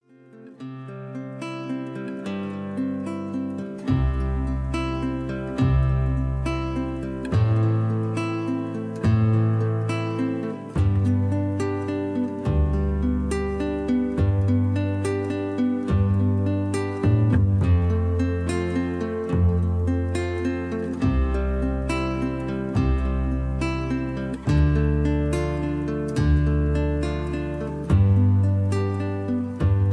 country rock